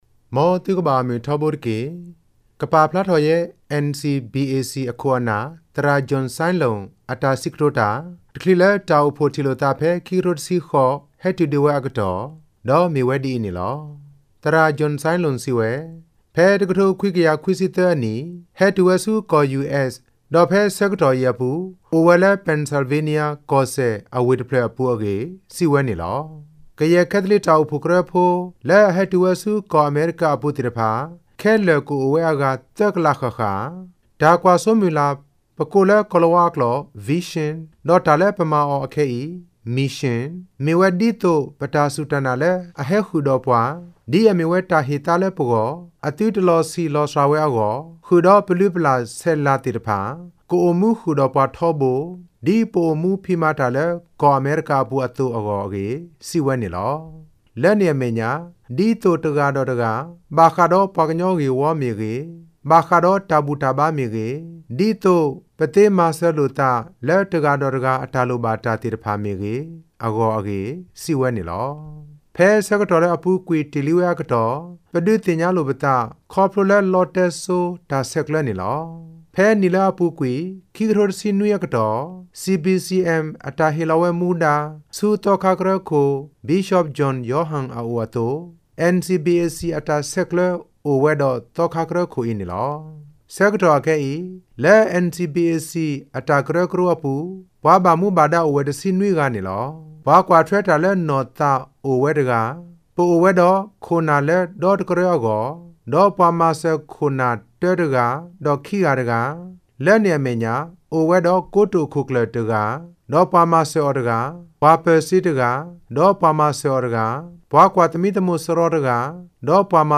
တခ်ဳးလ႕ တႈအိဥဖွိဥထံဥလိဏသးဖဲ 2018 ဟဲတုၚဒ္၀ဲအကတီႈ